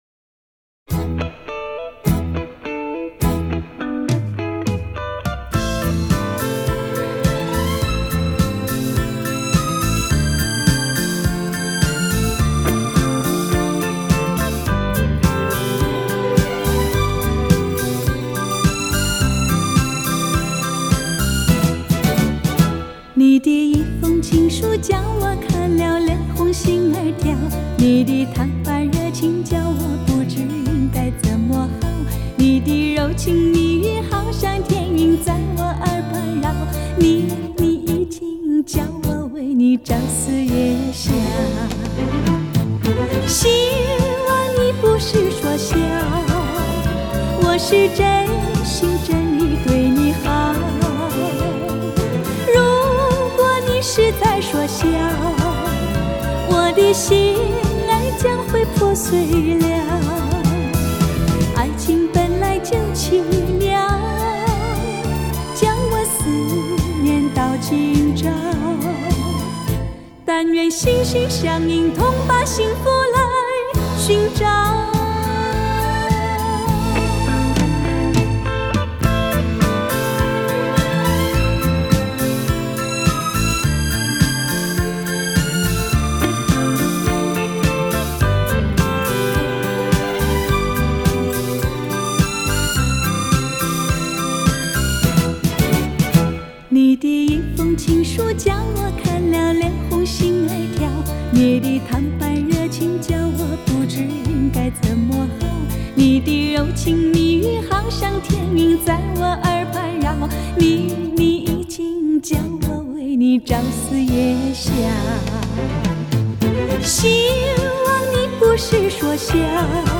DSD-CD，是将传统的音乐母带经过DSD技术处理，转换成可以在普通器材上播放的CD格式。